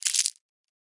糖果包装纸 " 糖果包装纸皱纹D
描述：用手指把塑料糖果的包装纸捏皱。
Tag: 糖果 起皱 包装